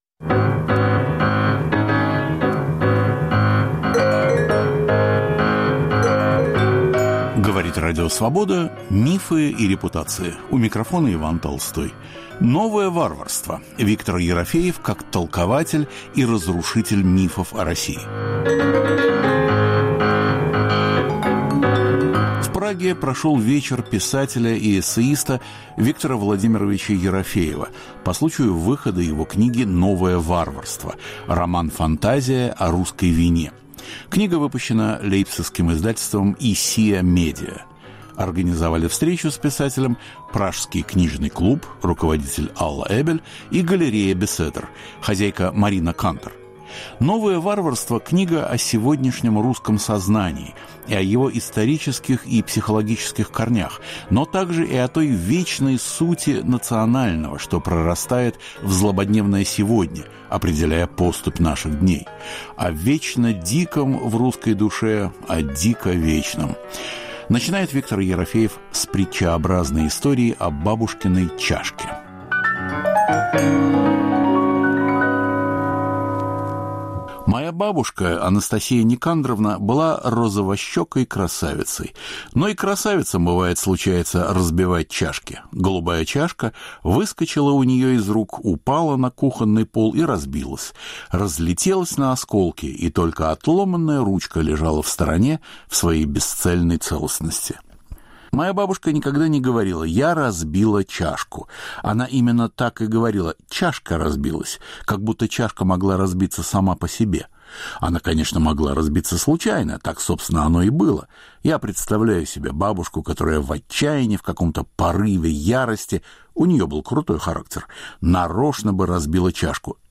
"Новое варварство" - роман Виктора Ерофеева о "русской вине". Беседа с писателем, представлявшим книгу в Праге.